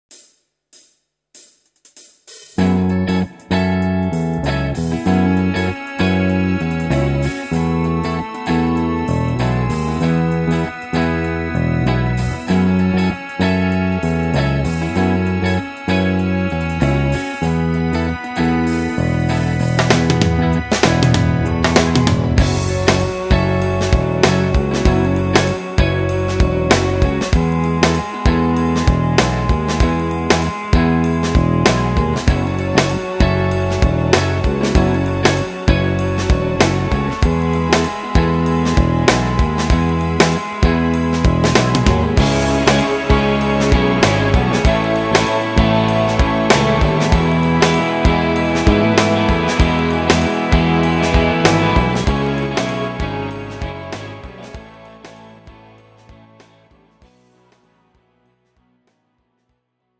팝송) MR 반주입니다.